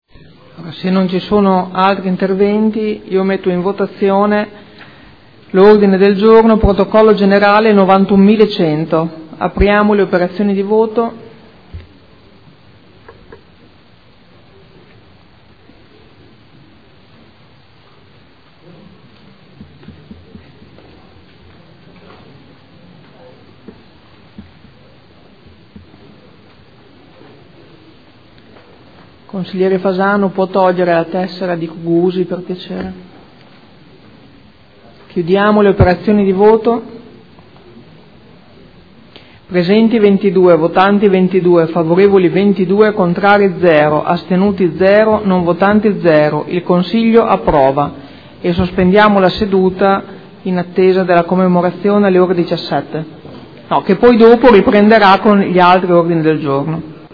Seduta del 2 luglio. Ordine del Giorno n° 91100.